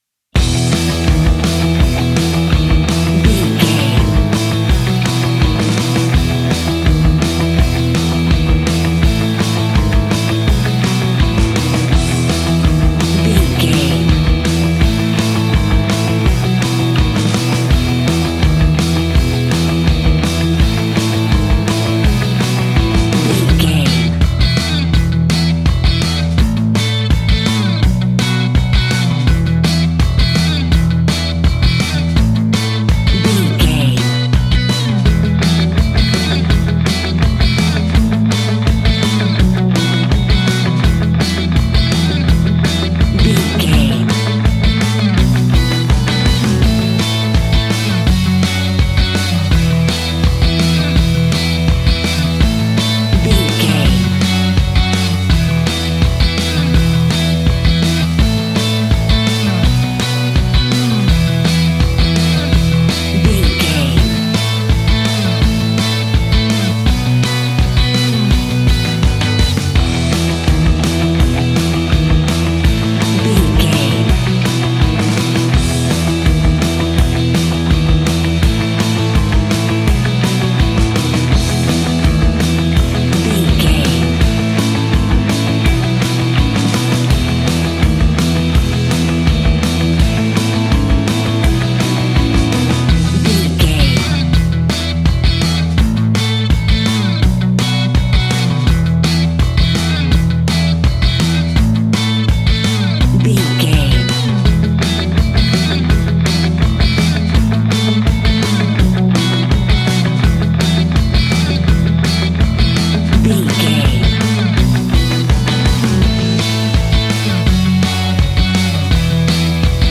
Ionian/Major
pop rock
indie pop
energetic
uplifting
instrumentals
upbeat
rocking
guitars
bass
drums
piano
organ